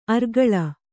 ♪ argaḷa